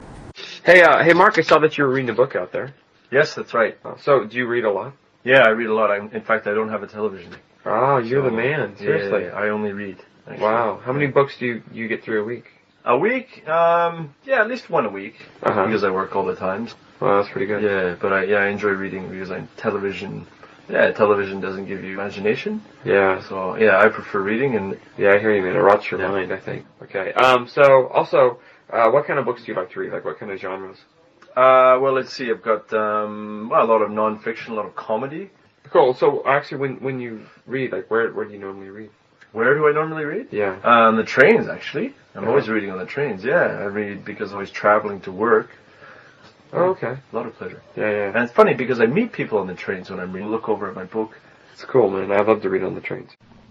英语高级口语对话正常语速06:阅读（MP3）